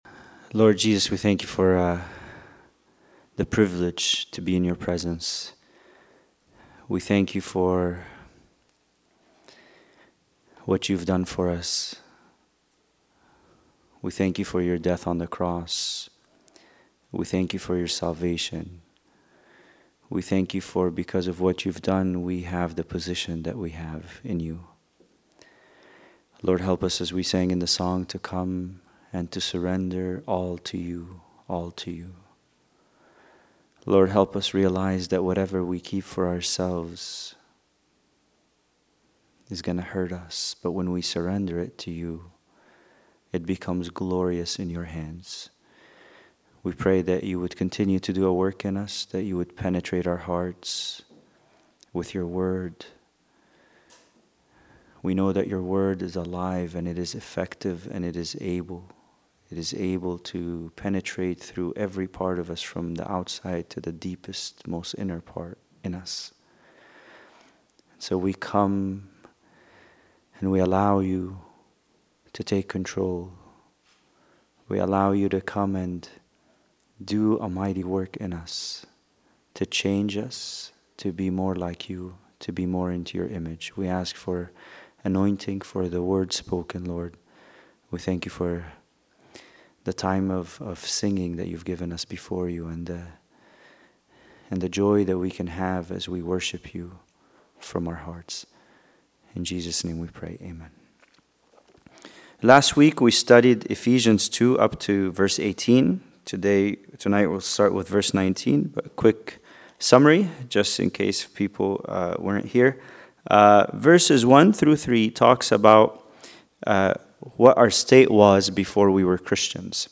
Bible Study: Ephesians 2:19-22